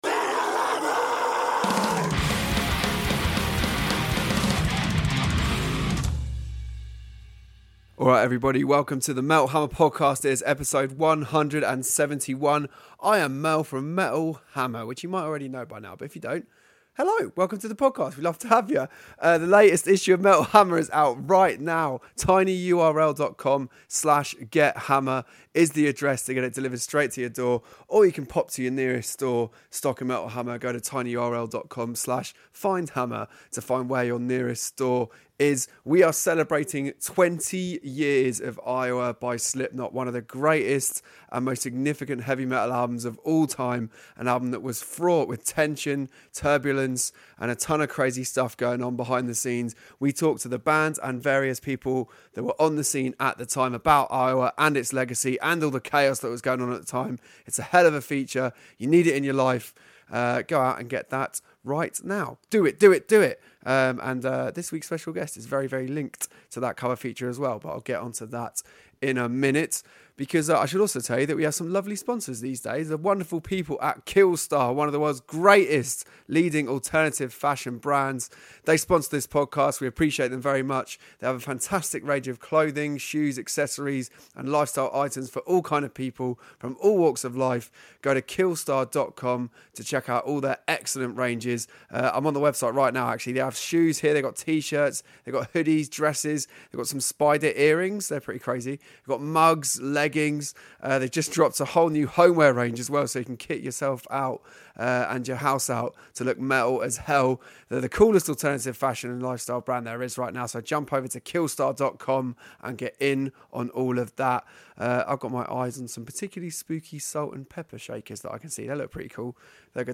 In a world exclusive new interview